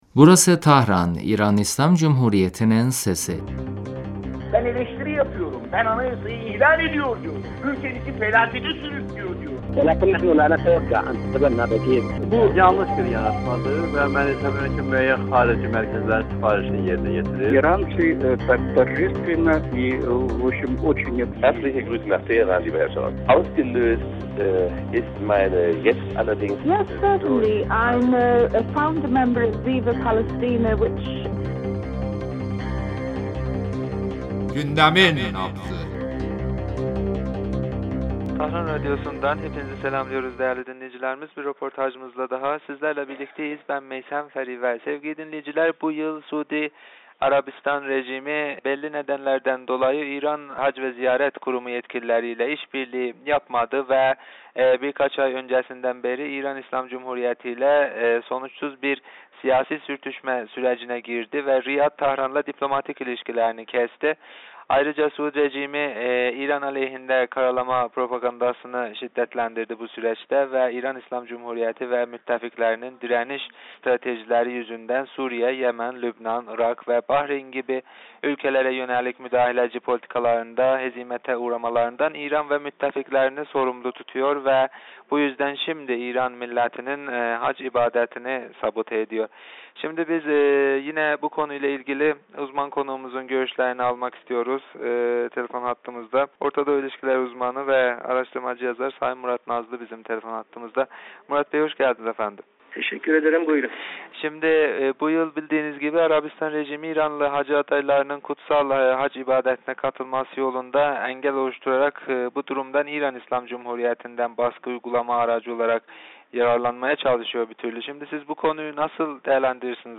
Röportajımızda